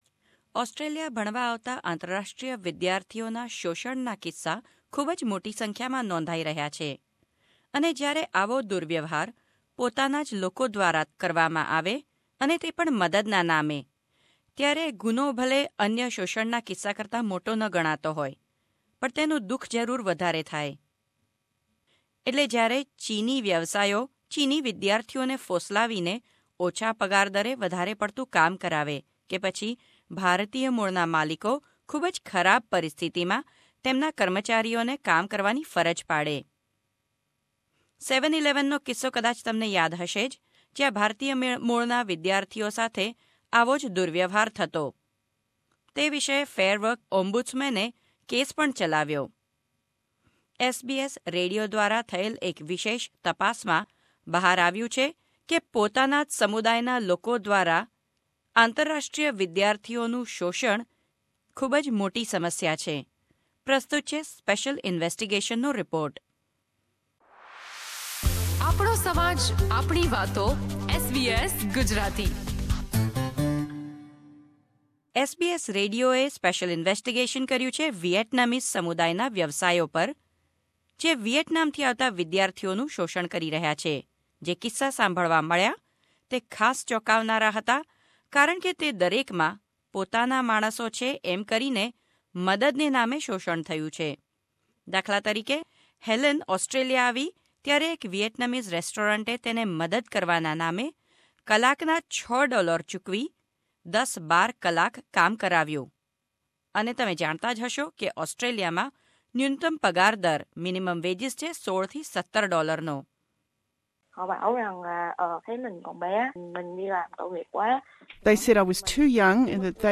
Last month an SBS reporter, posing as a job seeker, approached the owners in over 20 Vietnamese restaurants on the suburban streets of Melbourne. Armed with a hidden camera, this is what the "job seeker" encountered.